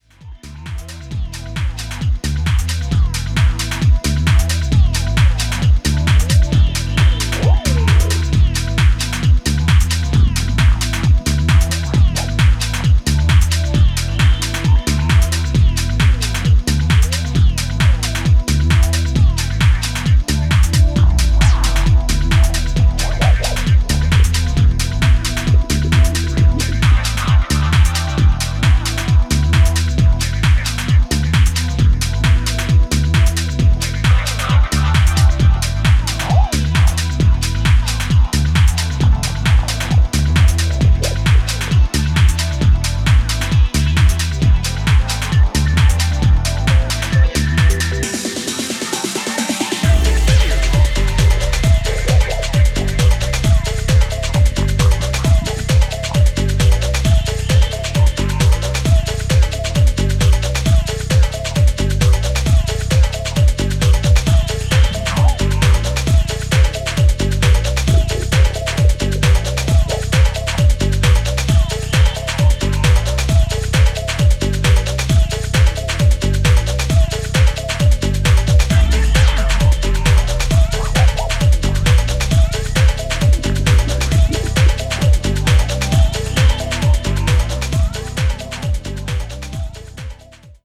一層ソリッドかつバウンシーにまとまっていて